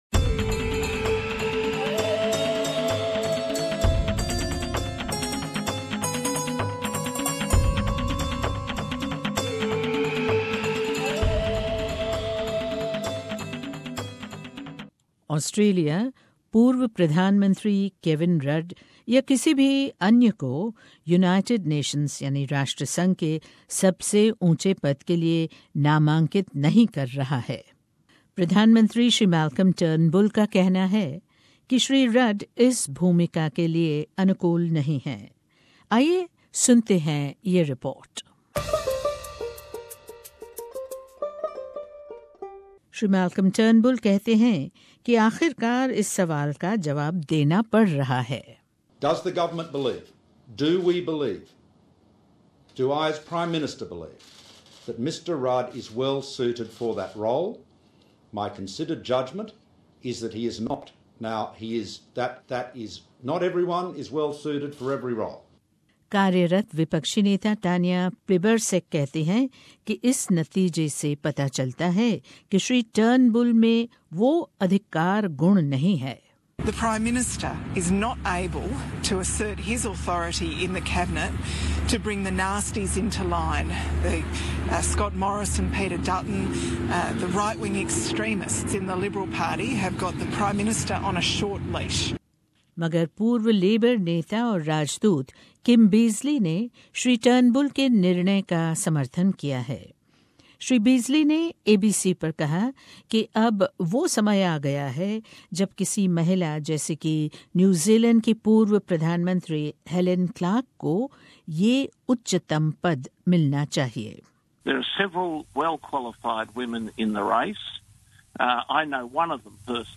ऑस्ट्रेलिया राष्ट्र संघ के सर्वोच्च पद के लिए पूर्व प्रधान मंत्री केविन रड या किसी और को नामांकित क्यों नहीं कर रहा है ? सुनिये ये रिपोर्ट.